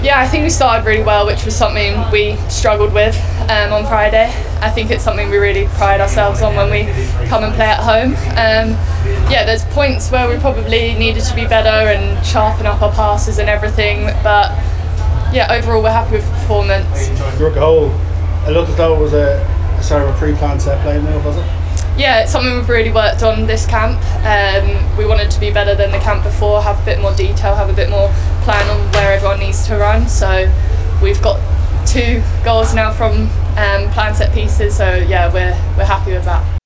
Anna Patten speaking with OFF THE BALL